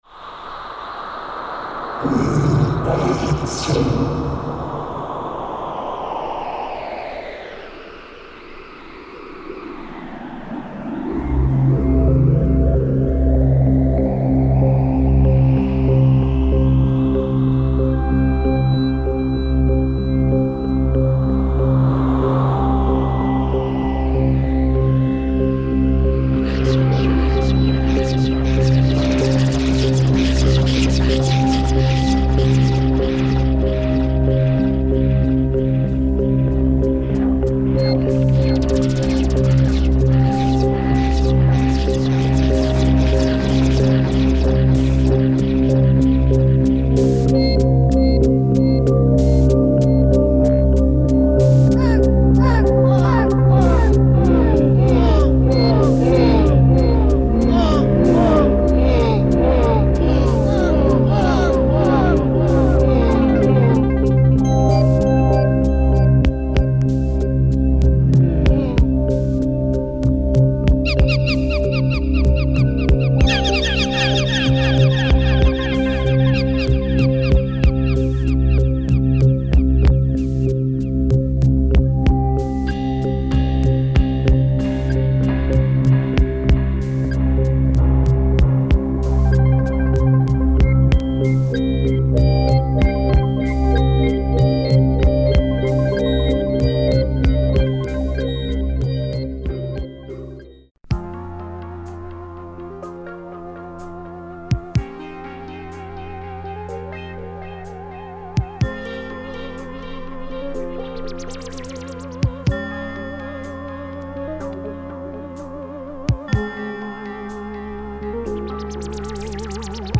Cult German electronic krautfunk ! Spacy progreesive sounds